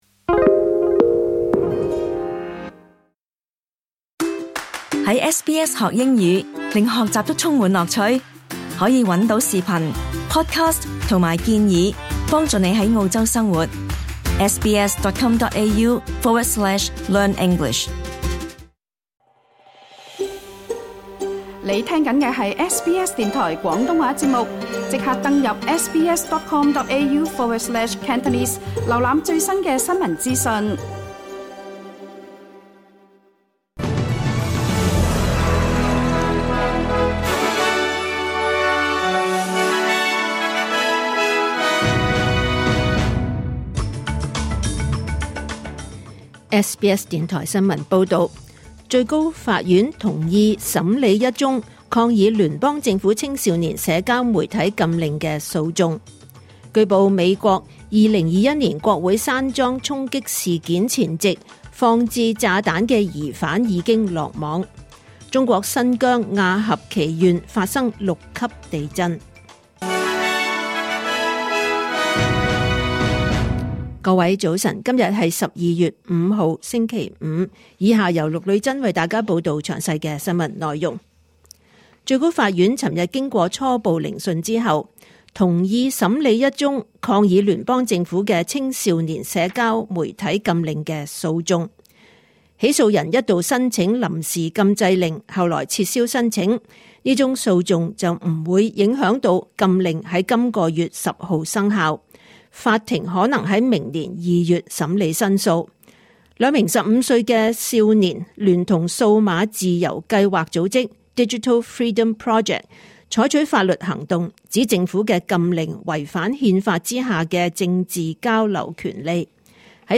2025年12月5日 SBS 廣東話節目九點半新聞報道。